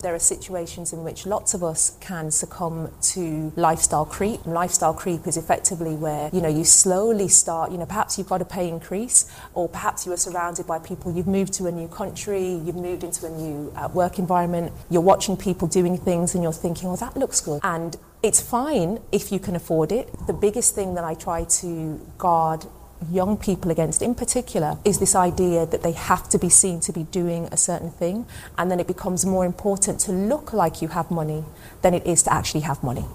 This took place on Tuesday, October 31st, 2023 during the Financial Investments Month (FIM) Festival 2023.